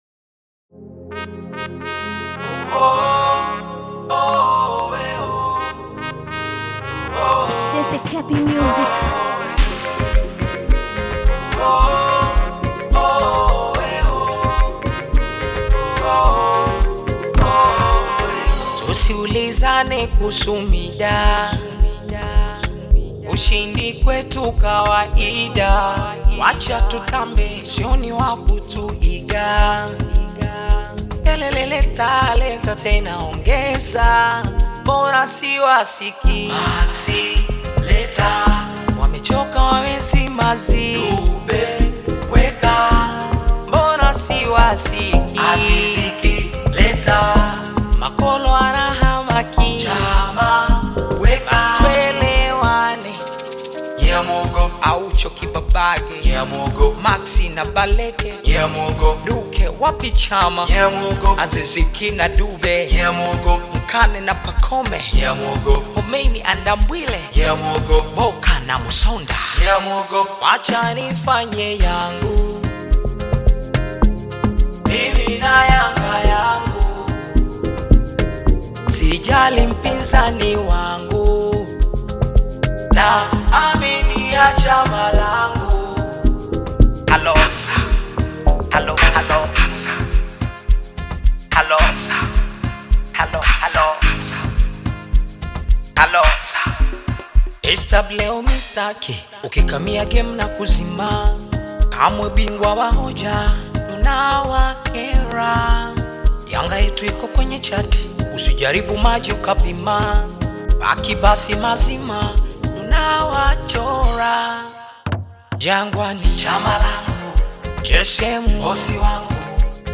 Tanzanian artist, singer, and songwriter
Bongo Flava